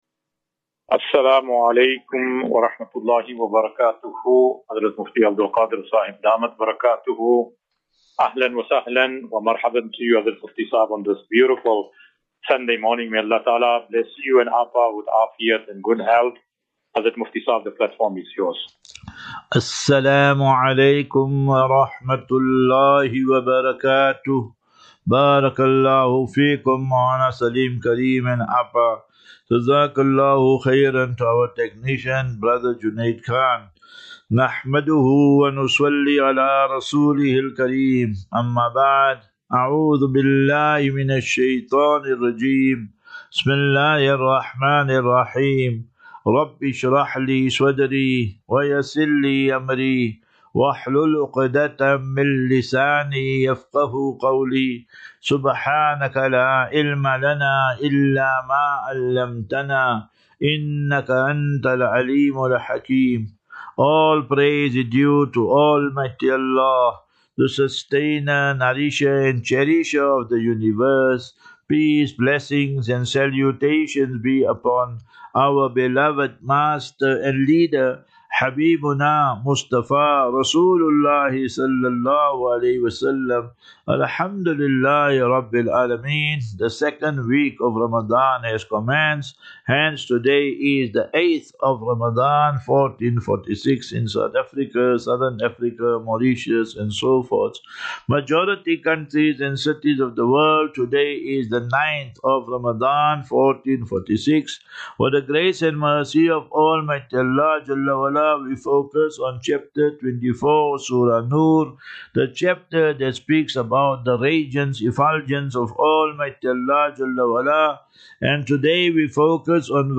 As Safinatu Ilal Jannah Naseeha and Q and A 9 Mar 09 March 2025.